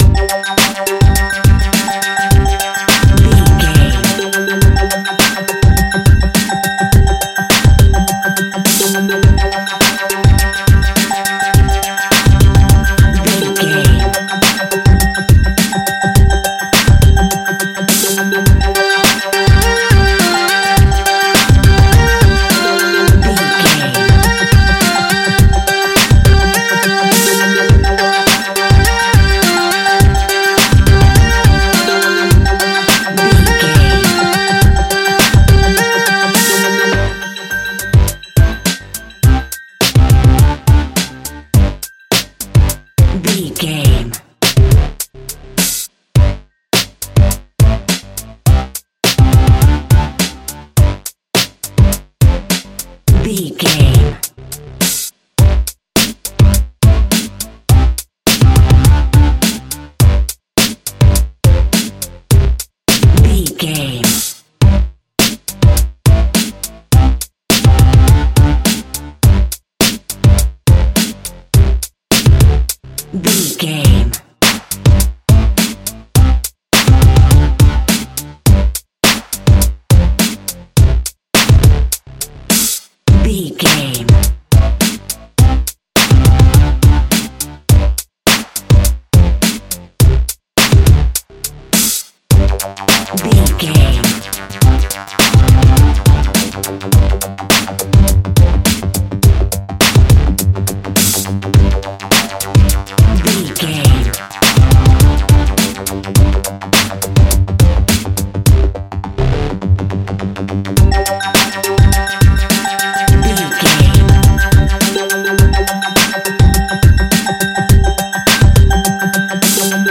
Hip Hop Instrumental Music.
Aeolian/Minor
funky
groovy
east coast hip hop
electronic drums
synth lead
synth bass